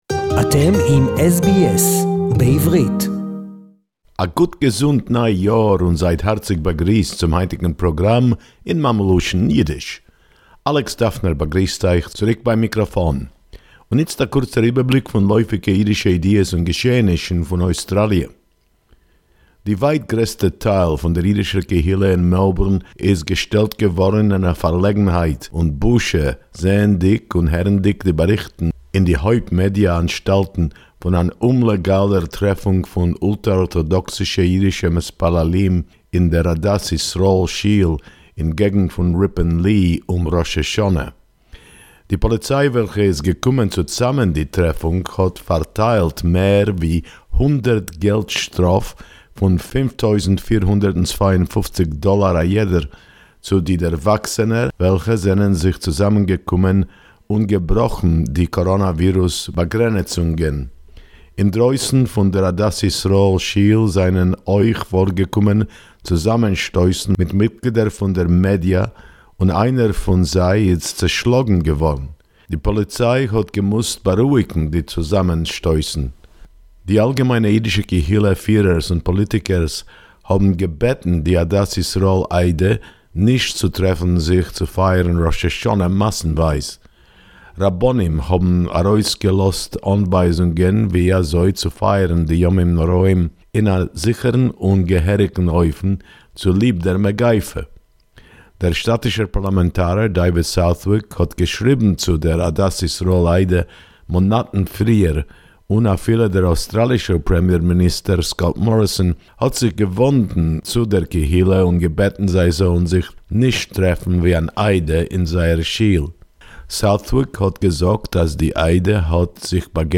SBS Yiddish report: Melbourne community embarrassed & upset by an illegal gathering of worshippers